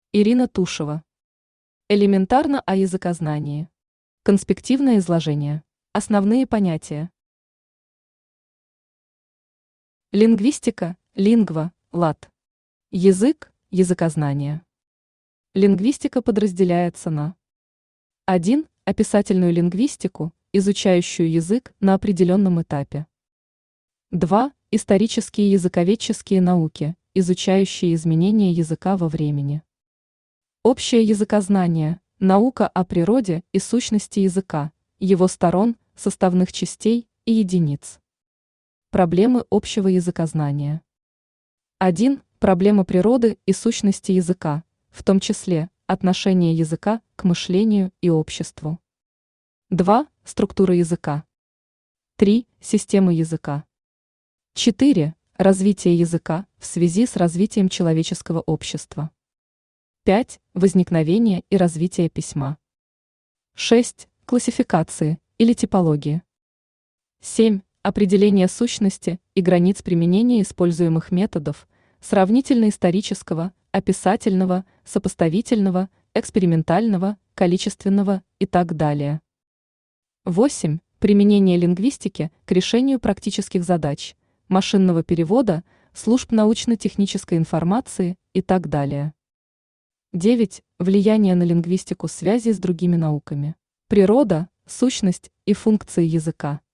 Аудиокнига Элементарно о языкознании. Конспективное изложение | Библиотека аудиокниг
Читает аудиокнигу Авточтец ЛитРес.